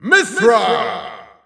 Announcer pronouncing Mythra's name in German.
Mythra_German_Announcer_SSBU.wav